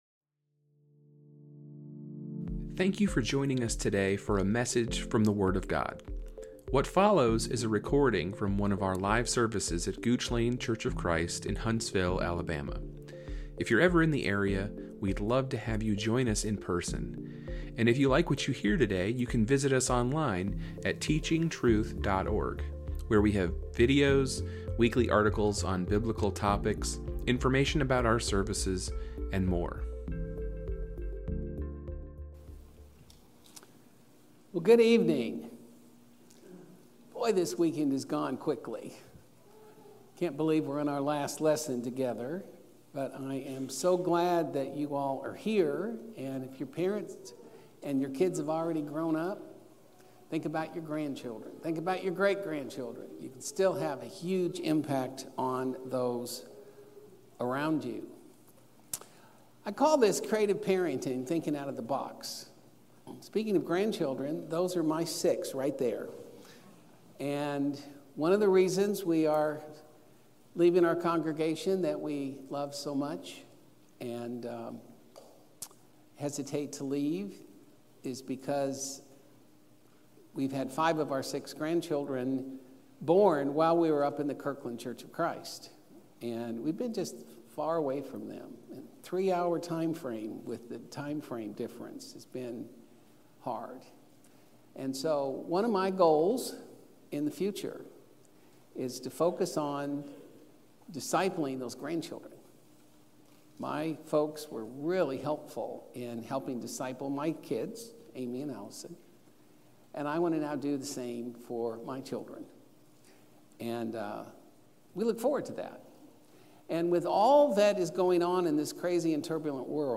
Lesson 8 of our 8-part gospel meeting